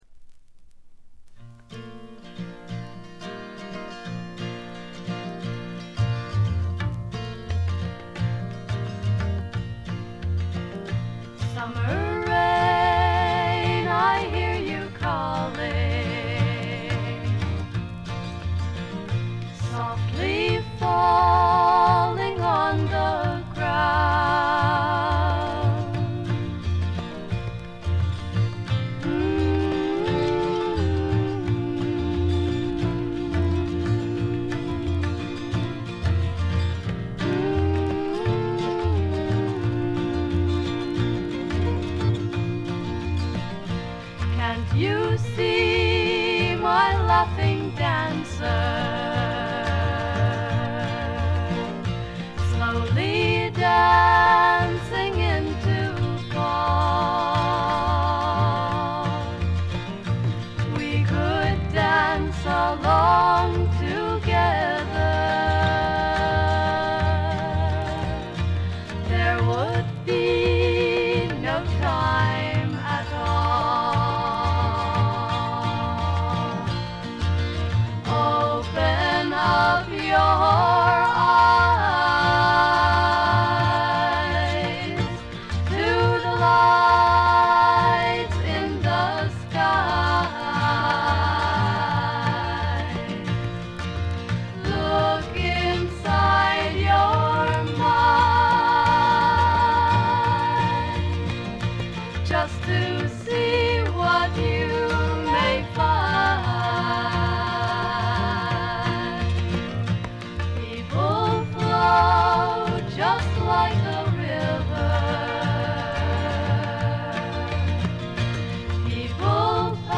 甘酸っぱい香りが胸キュンのまばゆいばかりの青春フォークの傑作。
Vocals, Guitar, Composed By ?